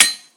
darkwatch/client/public/dice/sounds/surfaces/surface_metal8.mp3 at 8fb59b5c1758dc9a7aea9609f906d304ef90deb8
surface_metal8.mp3